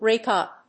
アクセントráke úp